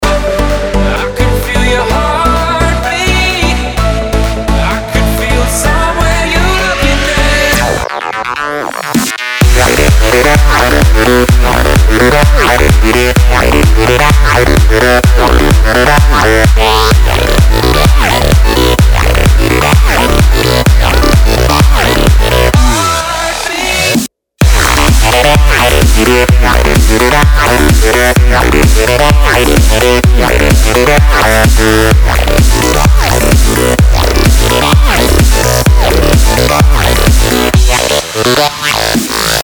• Качество: 320, Stereo
Progressive Electro